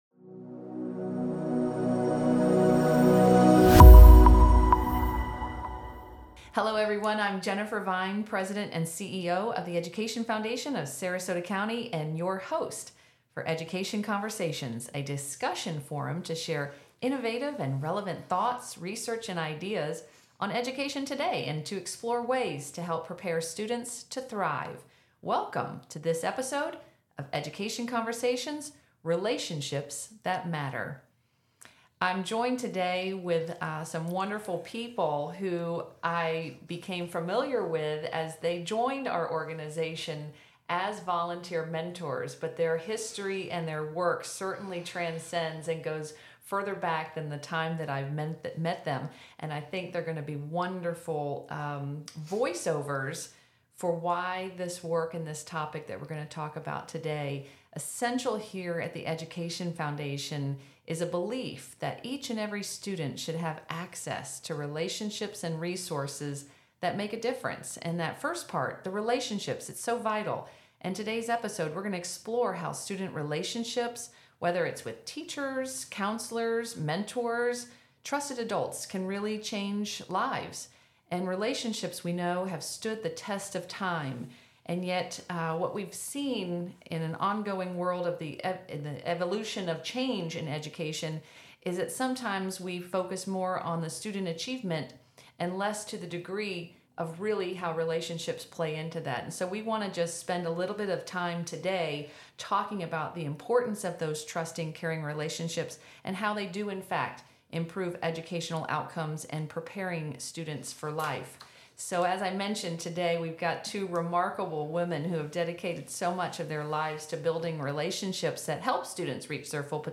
In this episode, we explore the power of relationships to change the lives of students. Two volunteer mentors for the Education Foundation of Sarasota County, both with extensive backgrounds in education and student support, share their experiences supporting students and discuss how trusting advisors can make a difference and how mentoring has reciprocal effects.